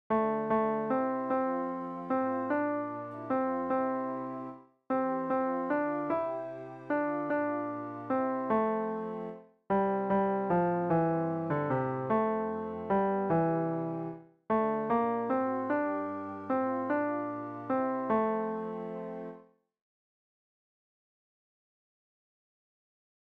Chorproben MIDI-Files 496 midi files